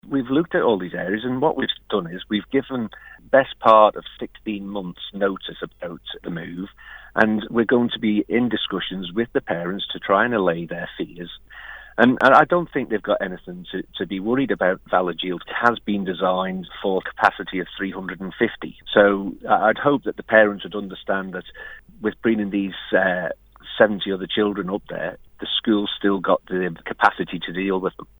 The Island's Education Minister Graham Cregeen says the parents don't have anything to worry about: